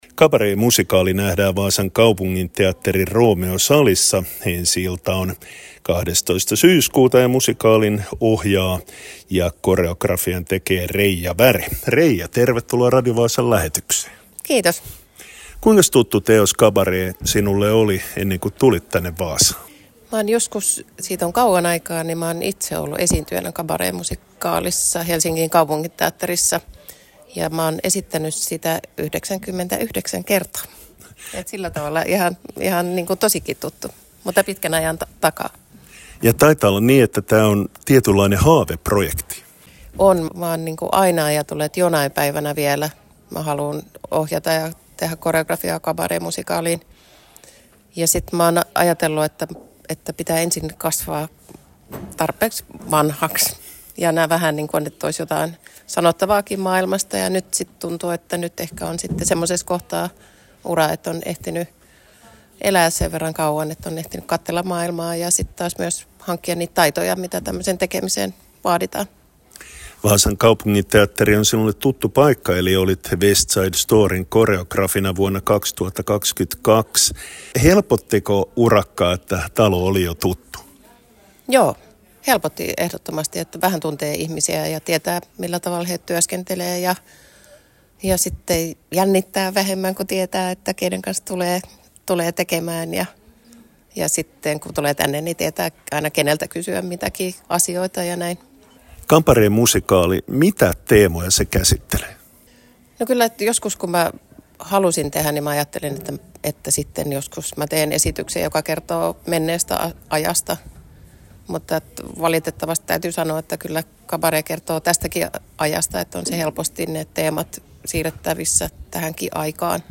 Ajankohtaista / Aktuellt